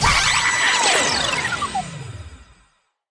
Block Exit Sound Effect
Download a high-quality block exit sound effect.